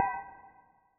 dash.wav